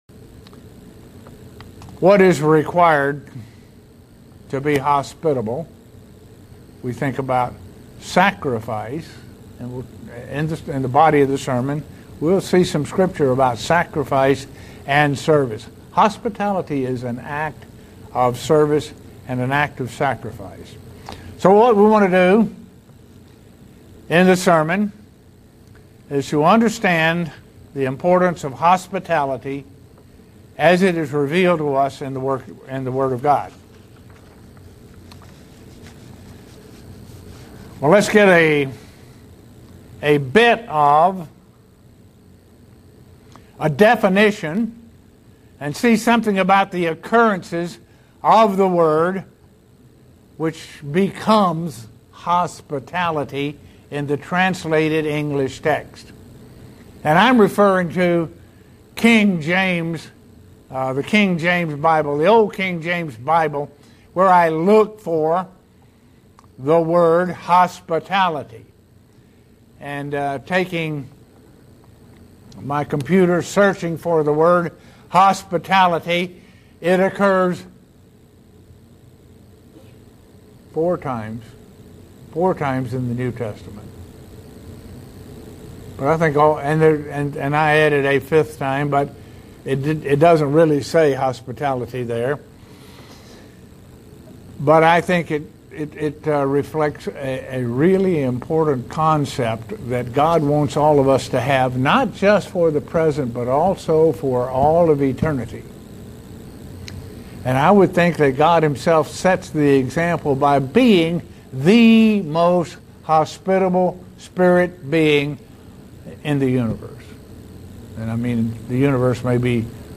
Given in Buffalo, NY
Print Understand the importance of Hospitality as it is reveled to us in the word of God. sermon Studying the bible?